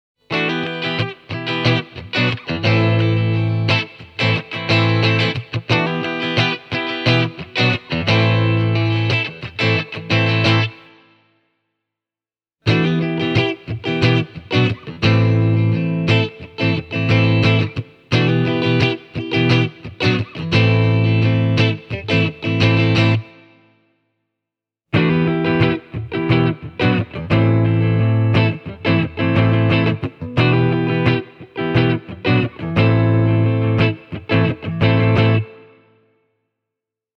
Here’s a clip of the TTE-50 Modern played straight into a Bluetone Shadows Jr. -combo (Shure SM57), starting with the bridge pickup: